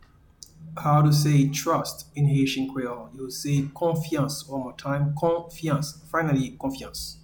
Pronunciation and Transcript:
Trust-in-Haitian-Creole-Konfyans.mp3